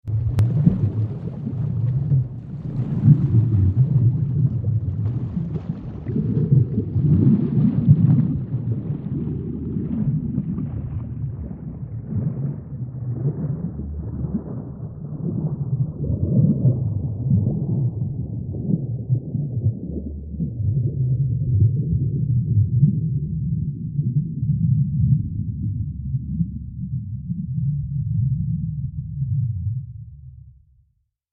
دانلود صدای دریا 3 از ساعد نیوز با لینک مستقیم و کیفیت بالا
جلوه های صوتی